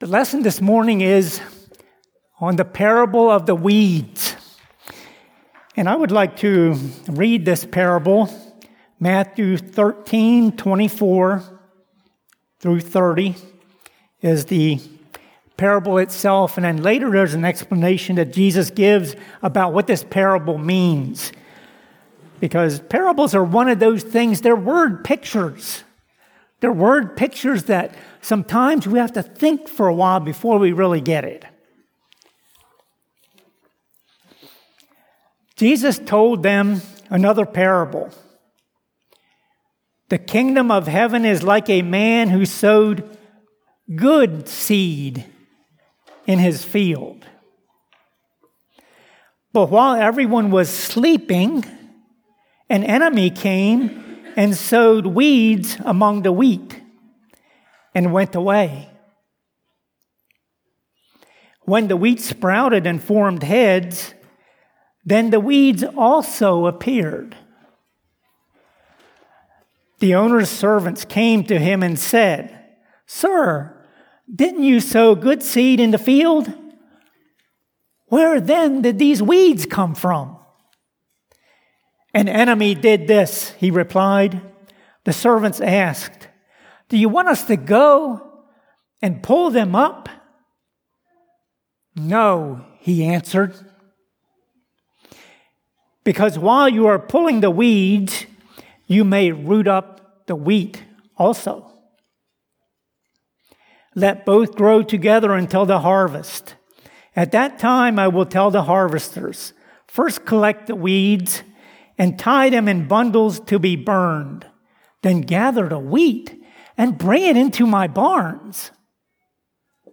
Maranatha Fellowship's Sunday Morning sermon recordings.
Sunday Sermons